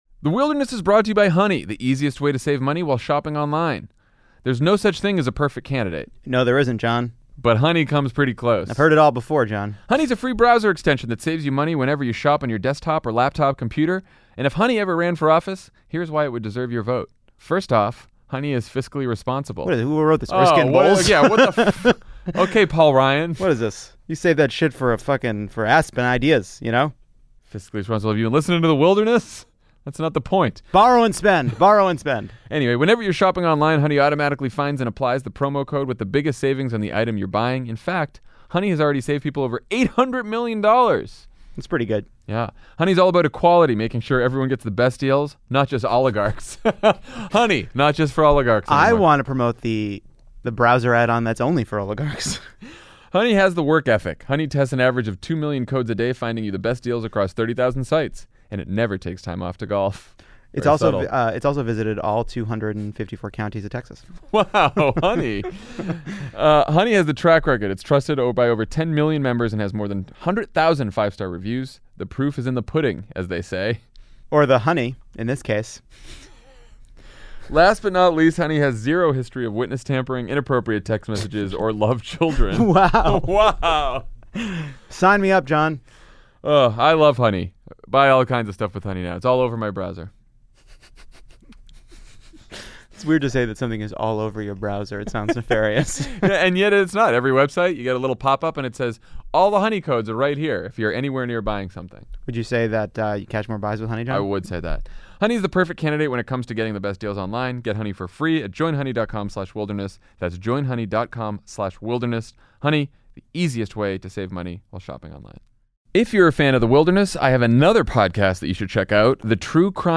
How can we recruit a new generation of winning candidates? Three young, recently-elected Democrats talk about what it’s like to run for office.